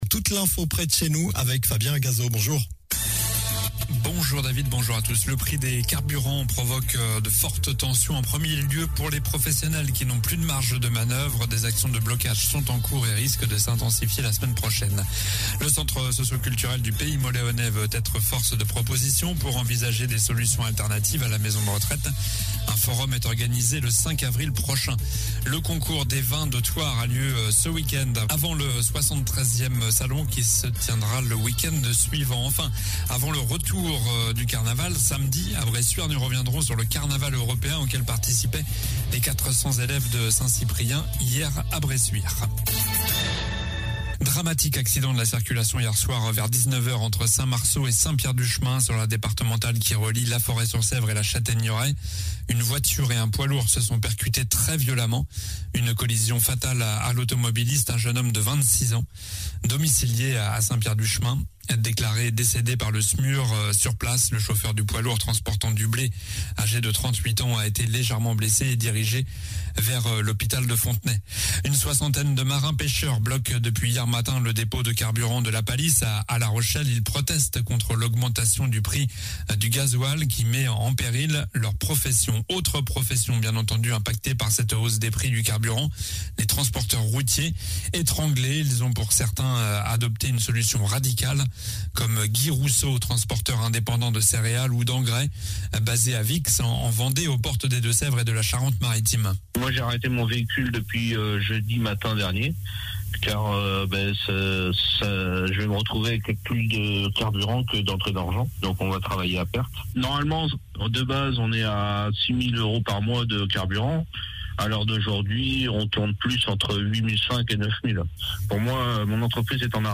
Journal du jeudi 17 mars (midi)